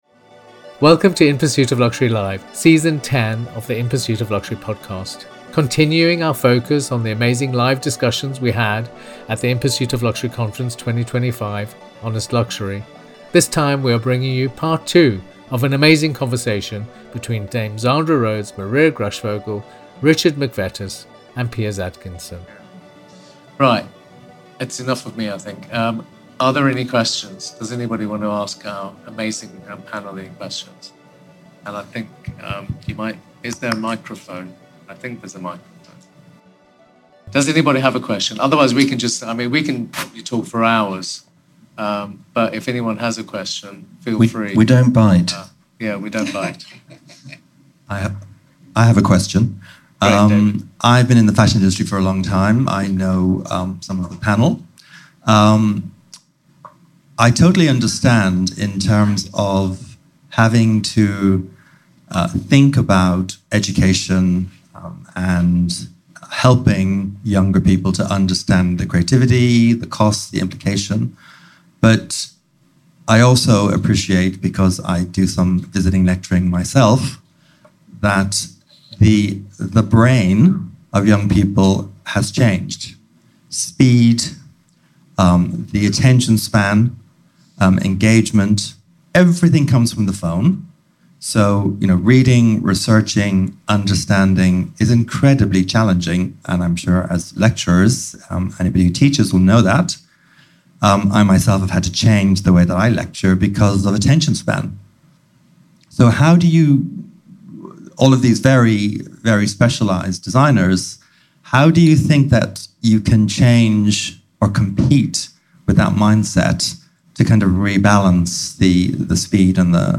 In conversation with Zandra Rhodes